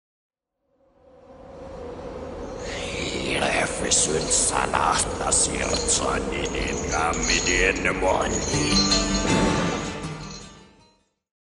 Genere: symphonic black metal
Incomprensibile